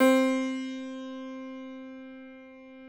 53l-pno10-C2.wav